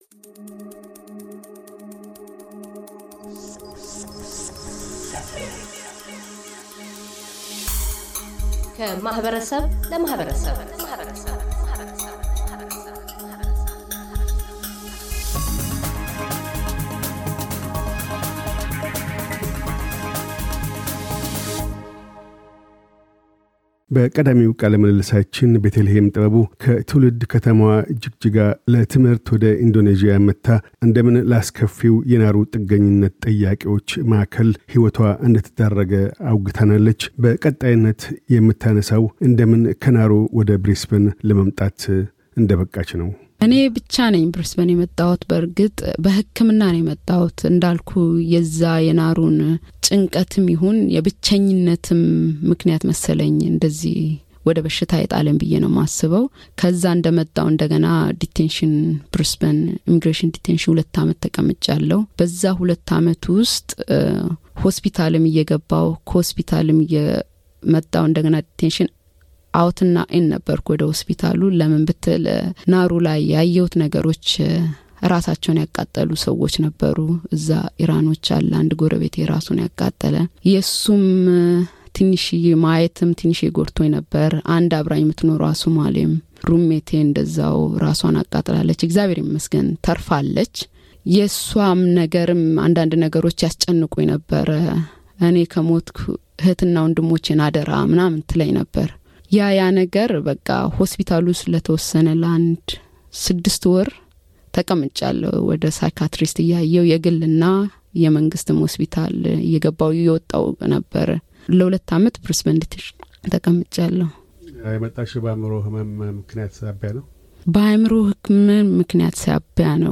የአውስትራሊያን ምድር ብትረግጥም ለተጨማሪ ሁለት ዓመታት ከአግልሎ ማቆያ ማዕከል ማምለጥ አልቻለችም። *አስጨናቂ ታሪክ ሲሰሙ መንፈስዎ የሚታወክ ከሆነ ይህን ቃለ ምልልስ አያድምጡ፤ ከታች ያለውንም ፅሑፍ አያንቡ።